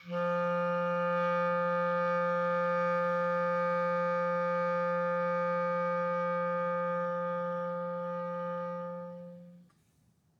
Clarinet
DCClar_susLong_F2_v2_rr1_sum.wav